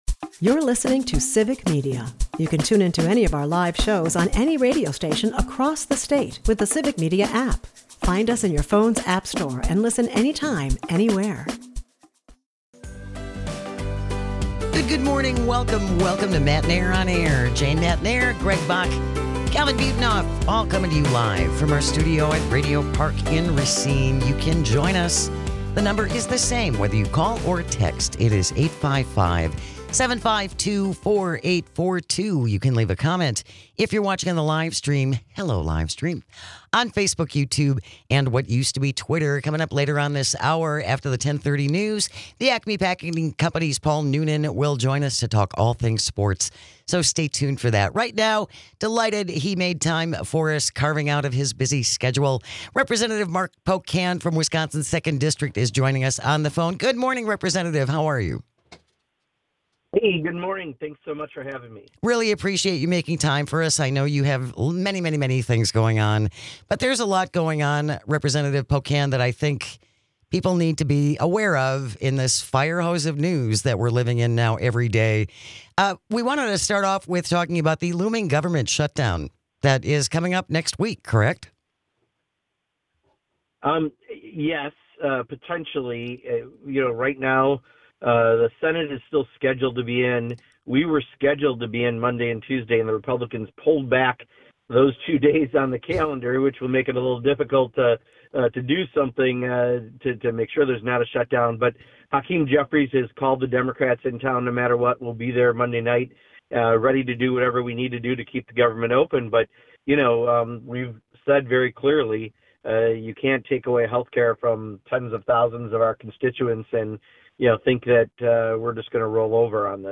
Congressman Mark Pocan (WI-2) joins the show to talk about the looming shut down, the importance of raising your voice whether at the ballot box or with your wallet and the true impact of the Big Bill For Billionaires.